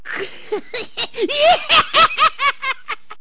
hehehaha.wav